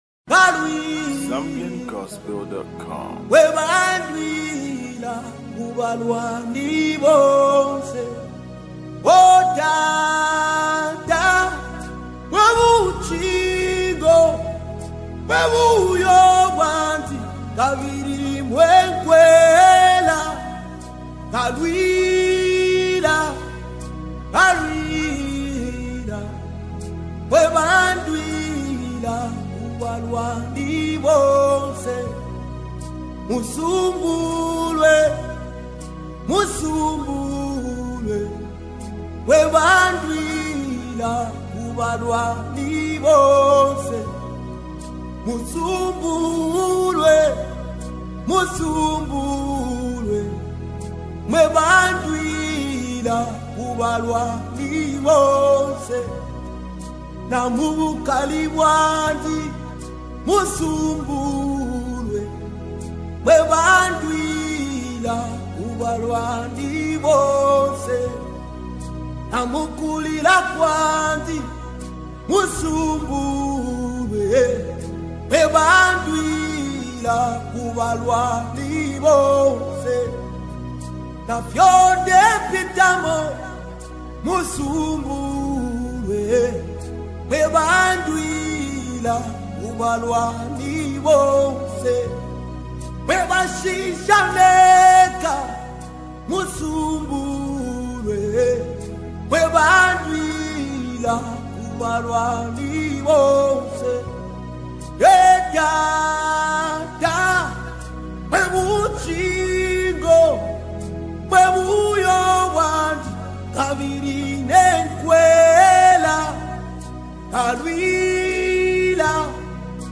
With lyrical declarations and soulful choruses